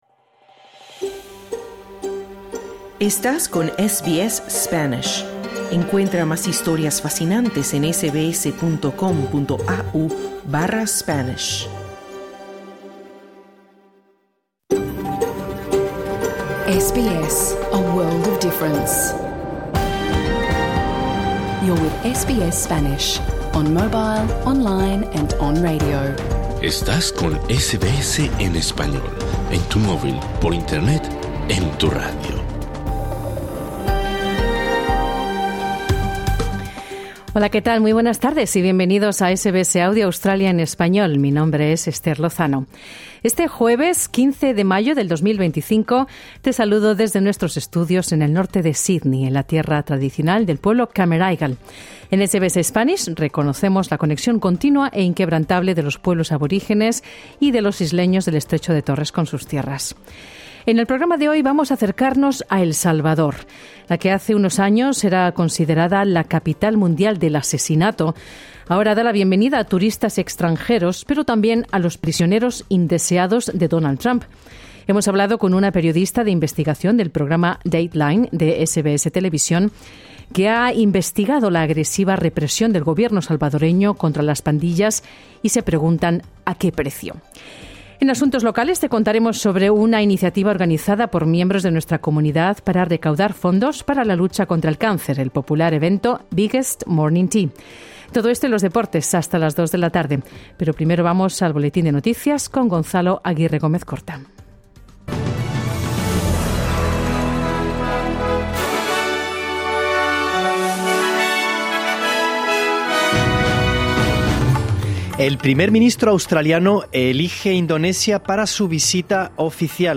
Programa en Vivo | SBS Spanish | 15 de mayo 2025 Credit: Getty Images